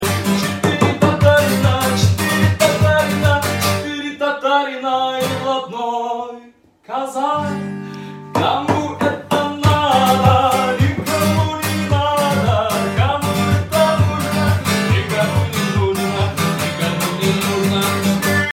• Качество: 128 kbps, Stereo